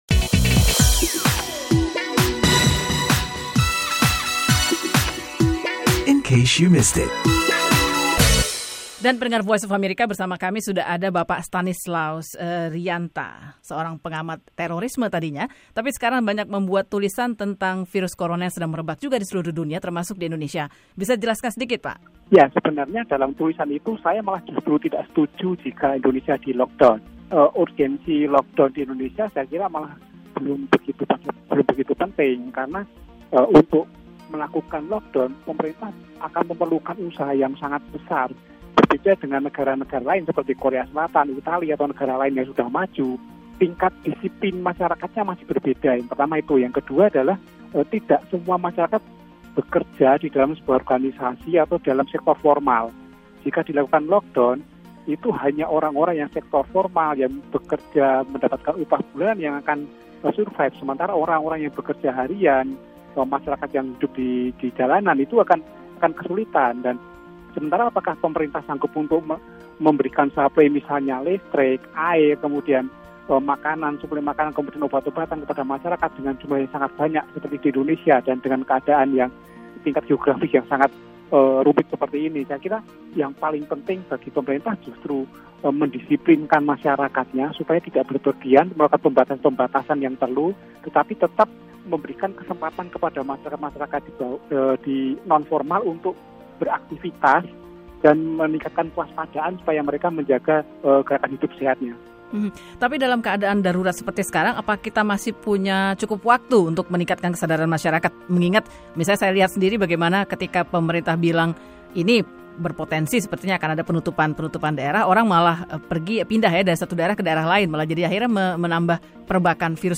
berbincang